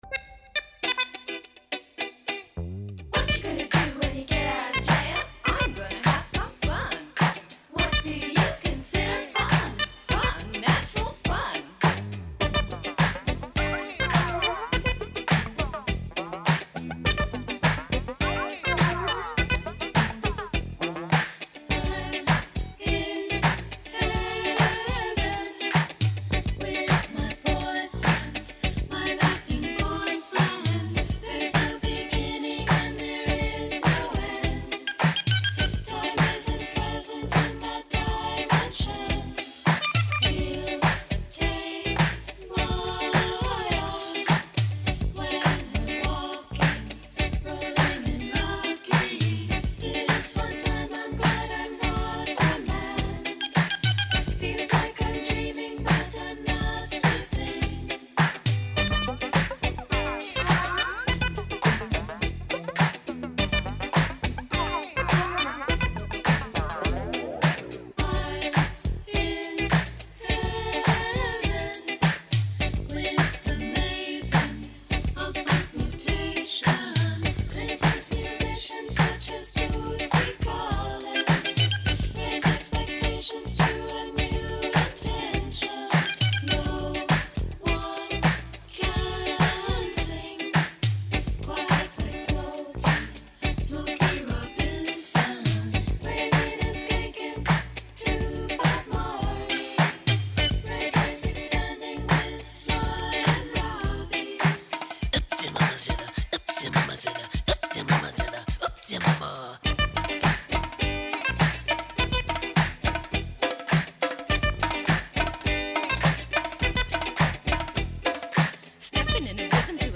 Ακούμε το σάουντρακ